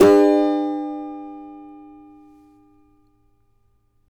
CAVA A MJ  D.wav